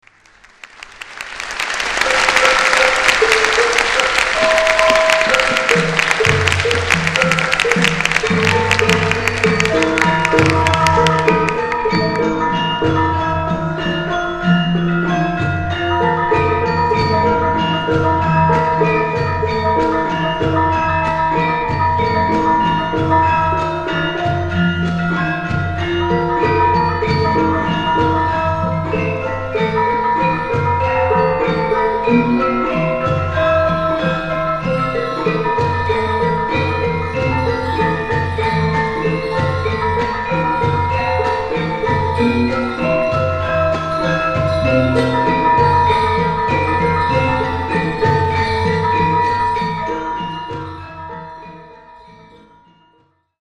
One thing to notice is that the UW gamelan is somewhat higher in pitch.
Played by Kyai Telaga Rukmi, the University of Wisconsin-Madison Javanese gamelan ensemble, on 27 April 2002.
This excerpt in the pélog scale is taken from a live performance of a dance drama called "Sinta Obong".
This excerpt begins with swelling applause because the dancers had just walked off the stage at the end of the previous piece and the audience thought the concert was finished.
You will hear me play the buka (introduction) and then one gongan of the piece.